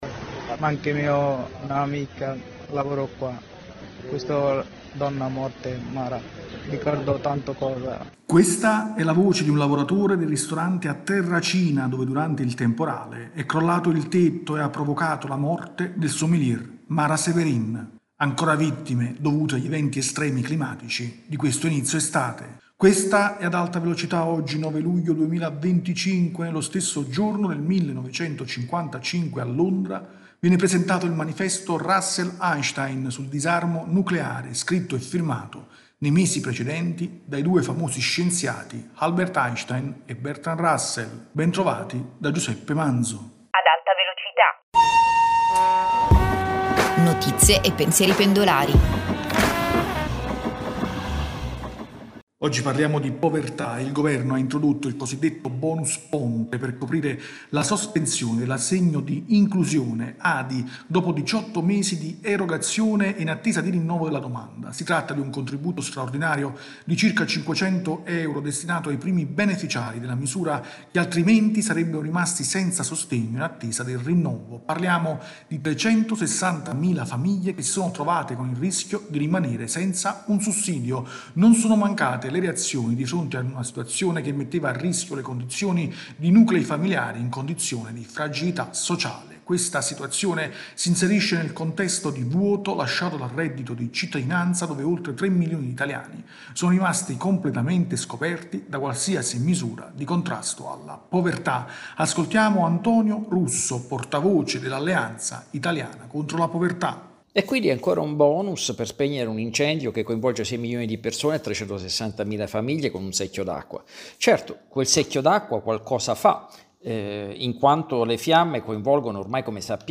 rubrica quotidiana